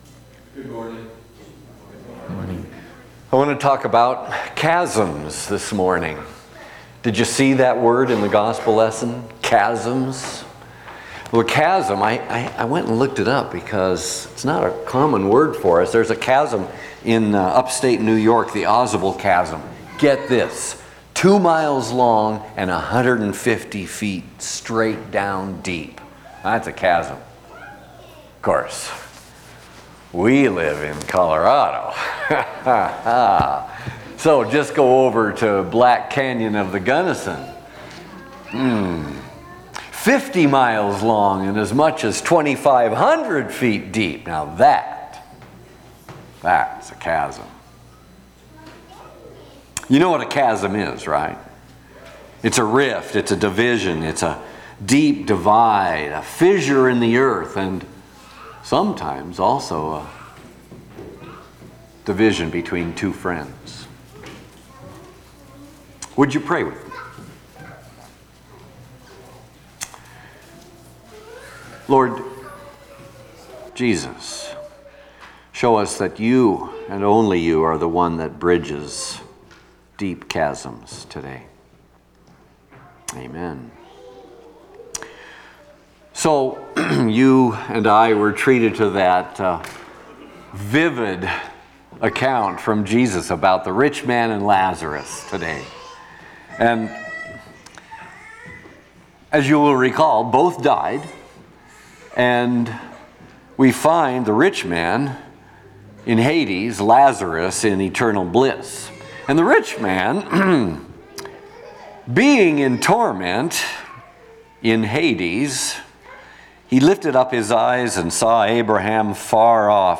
Sixteenth Sunday after Pentecost&nbsp
Trinity Lutheran Church, Greeley, Colorado There Is A Chasm...